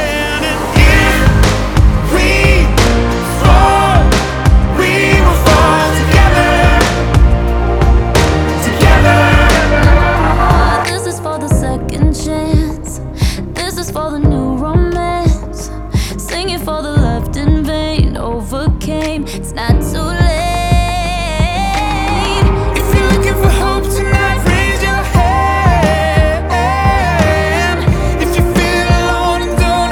Genre: Christian & Gospel